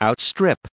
556_outstrip.ogg